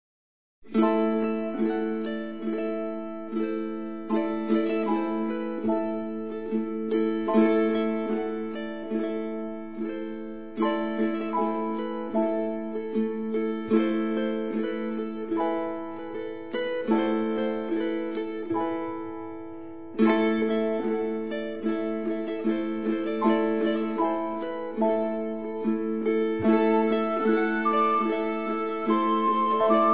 hammered dulcimer